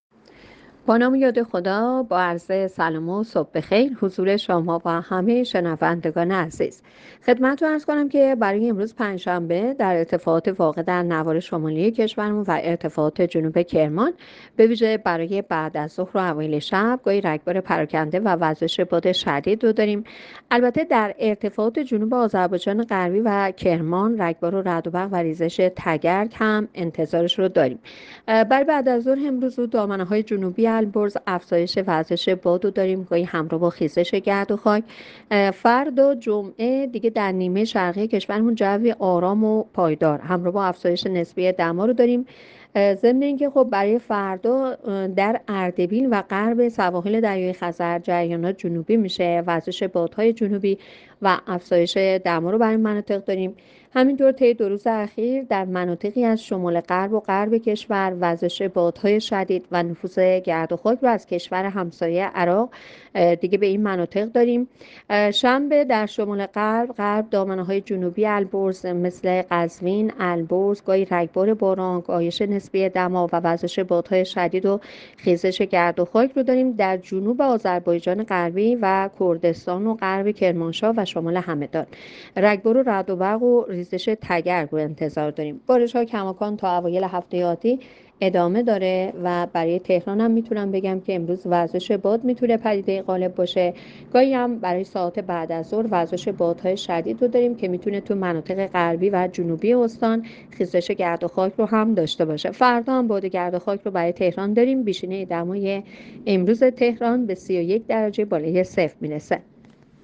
گزارش رادیو اینترنتی پایگاه‌ خبری از آخرین وضعیت آب‌وهوای ۴ اردیبهشت؛